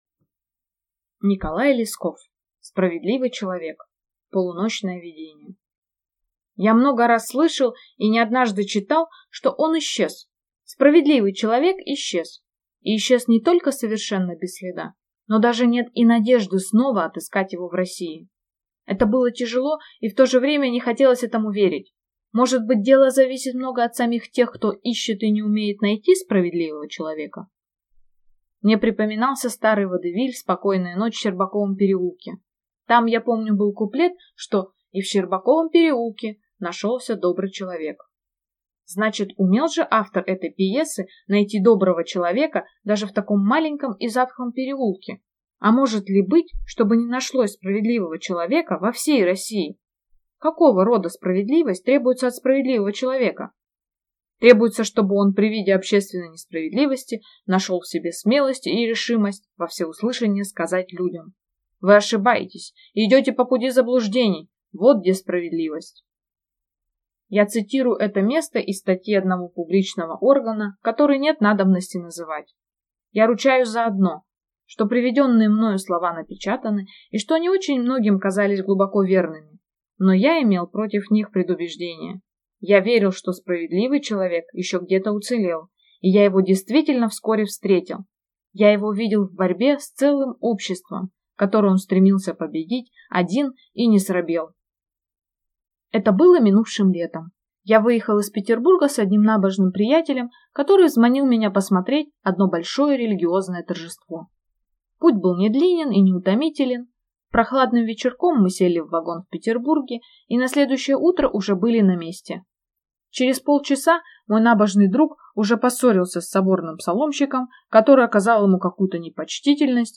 Аудиокнига Справедливый человек | Библиотека аудиокниг
Прослушать и бесплатно скачать фрагмент аудиокниги